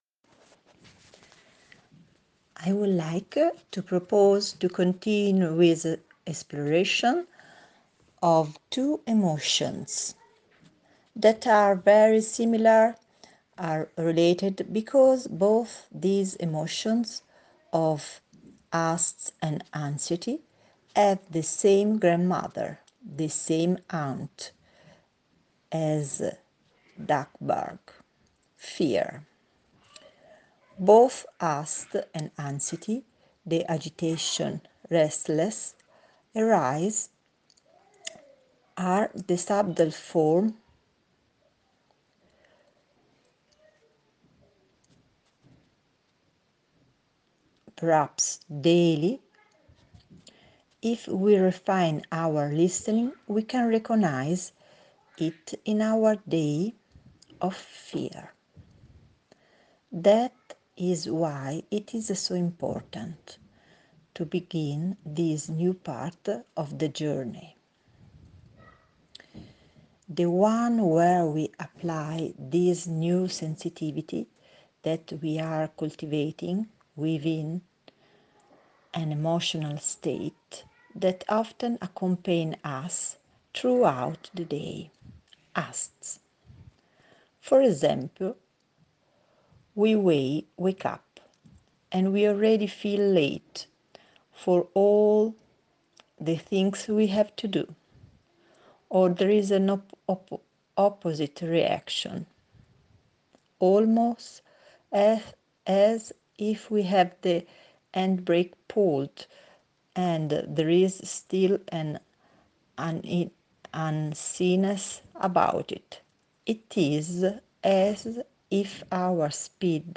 2 – Deep relaxation and practice of the week (duration: 21m)